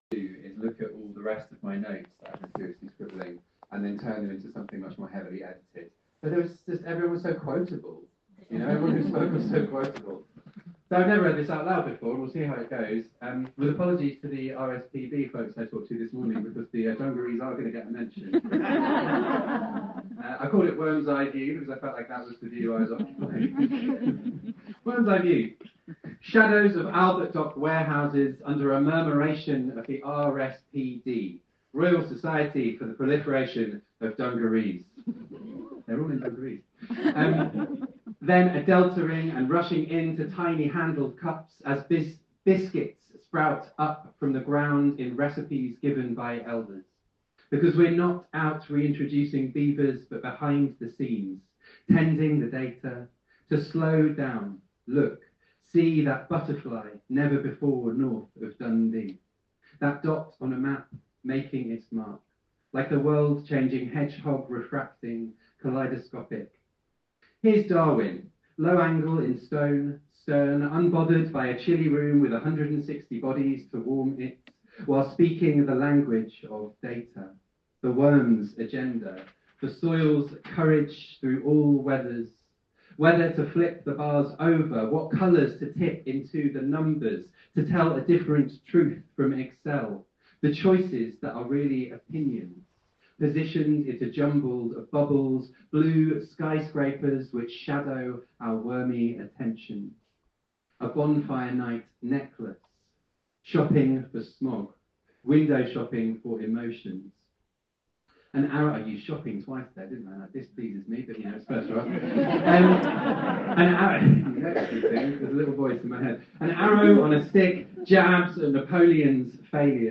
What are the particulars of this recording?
(The volume is quiet, but audible).